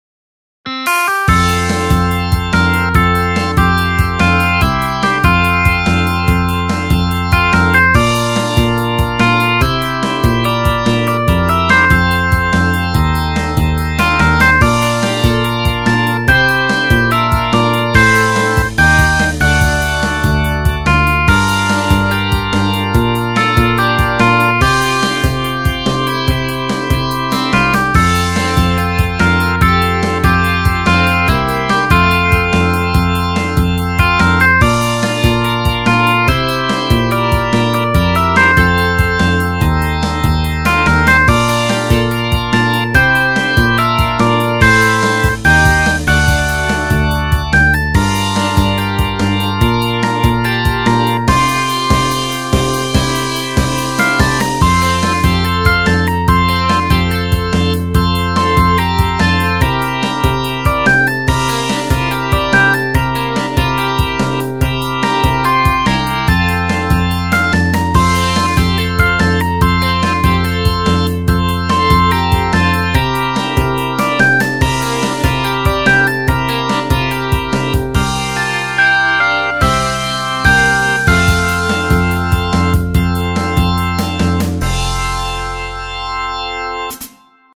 インストゥルメンタルエレクトロニカショートポップ